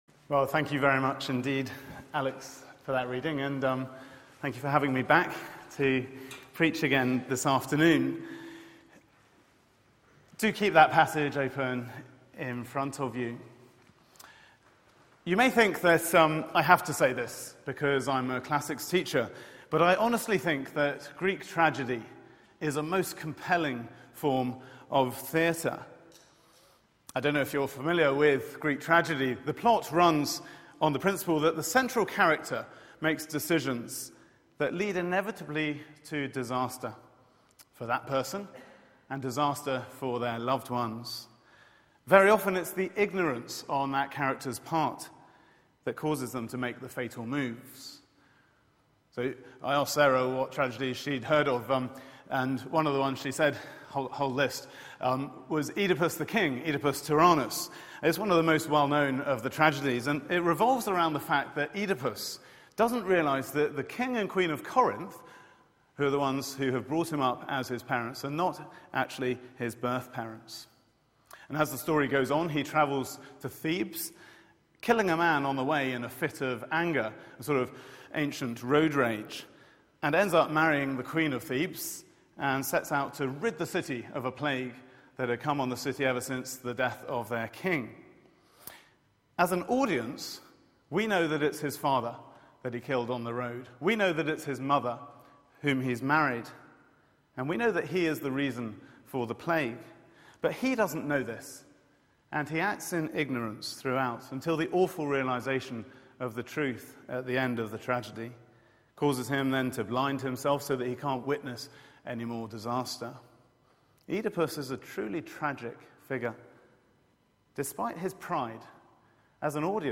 Media for 4pm Service on Sun 30th Mar 2014 16:00 Speaker